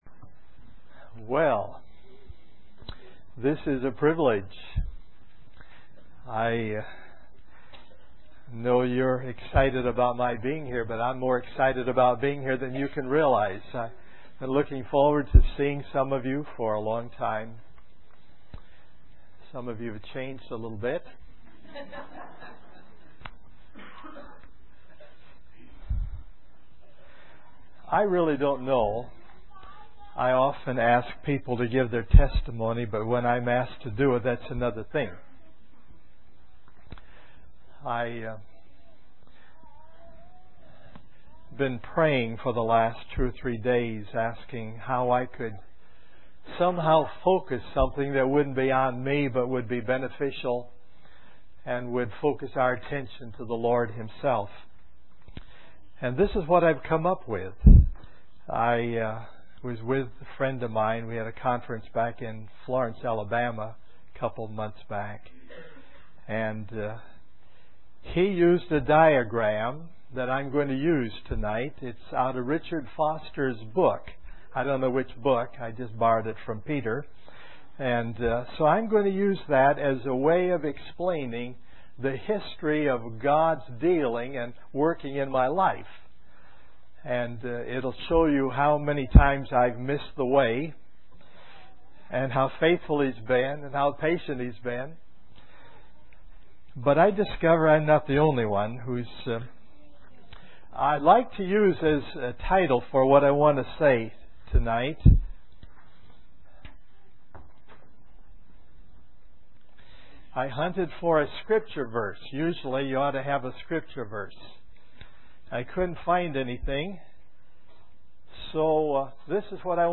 In this sermon, the speaker shares his personal journey of faith and how God has been faithful and patient with him despite his mistakes. He emphasizes the importance of keeping the main thing, which is the gospel, at the forefront of our lives.